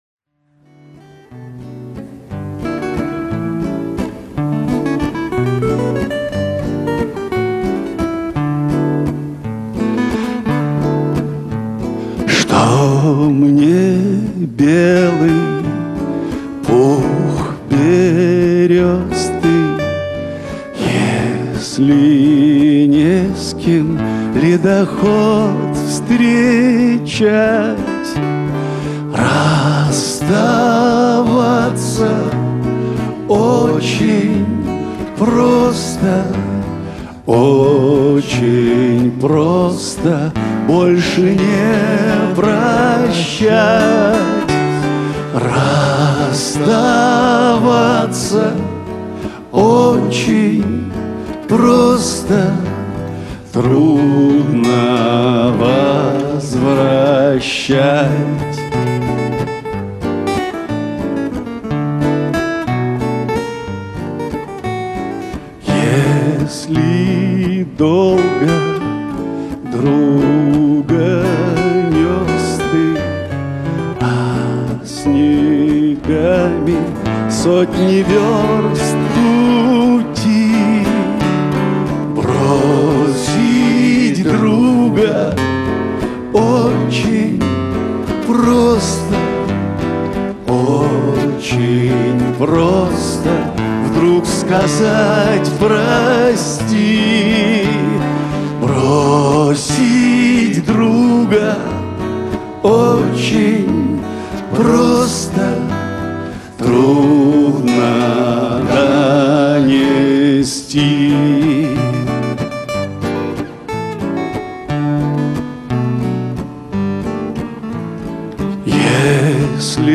Недавно в клубе Эльдар состоялся концерт музыки Петра Тодоровского.
Первые 3 записи - с этого концерта.